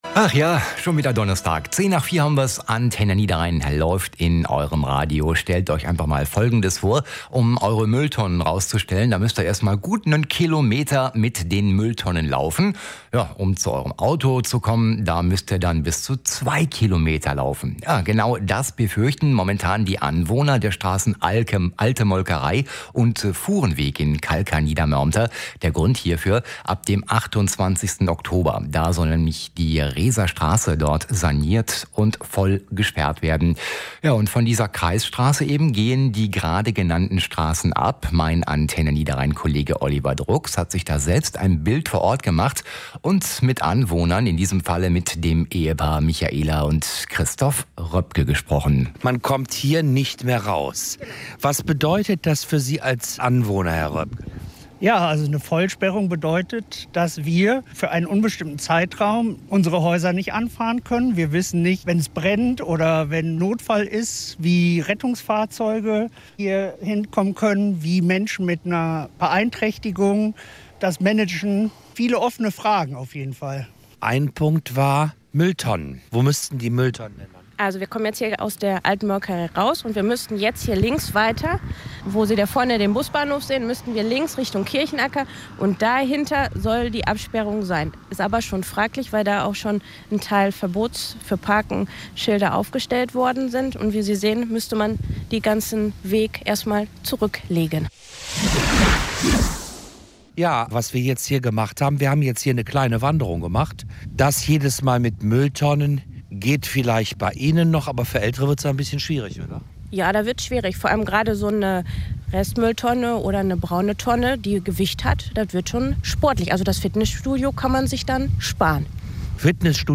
anwohner_niedermoermter.mp3